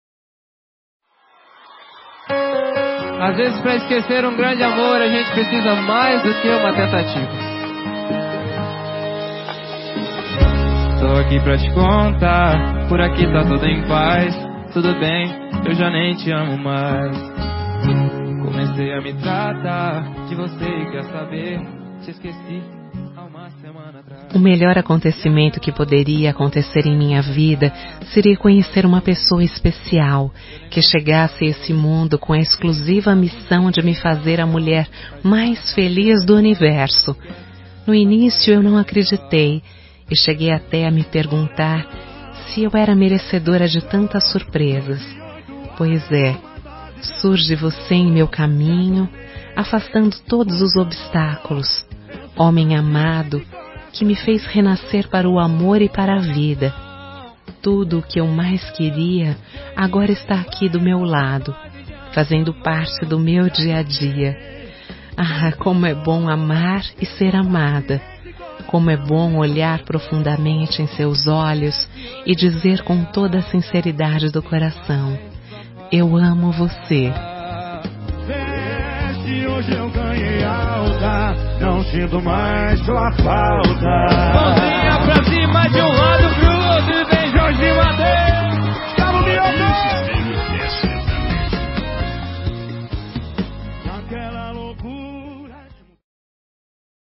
Telemensagem Declaração Romântica – Voz Feminina – Cód: 09827